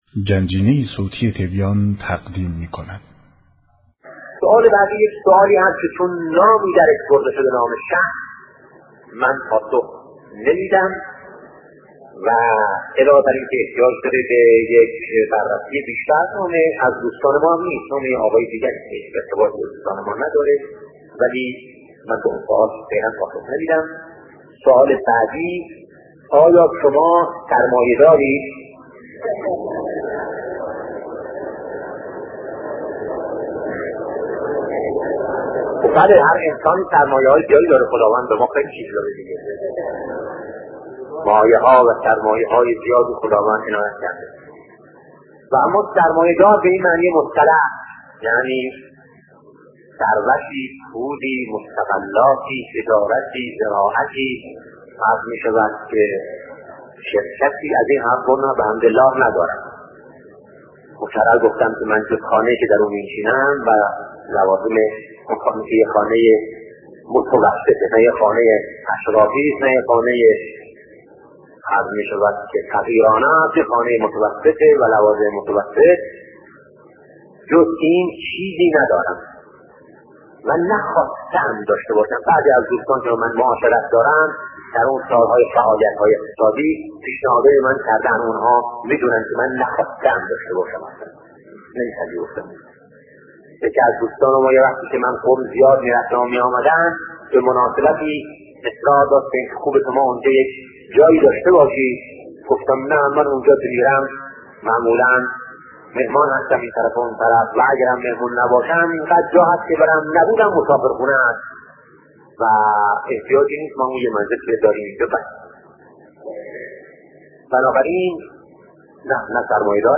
صوتی از شهید بهشتی، پرسش و پاسخ به سوالات مردم -بخش‌اول